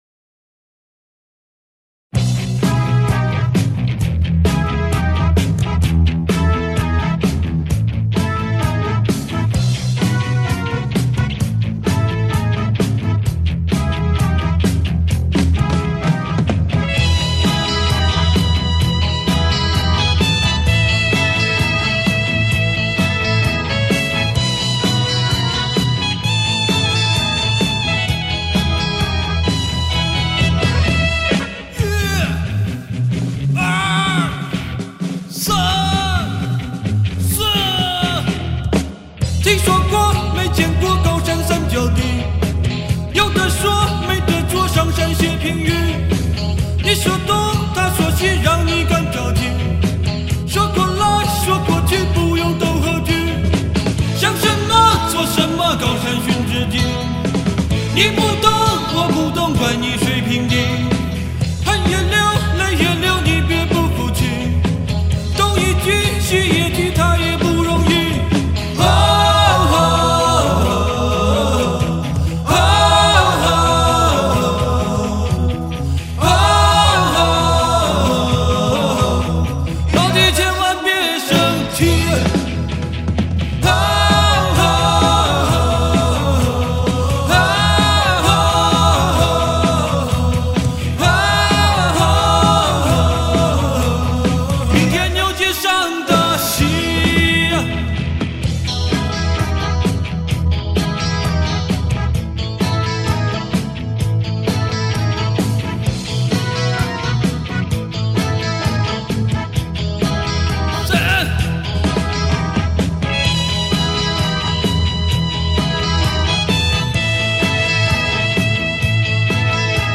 演唱、伴唱、吆喝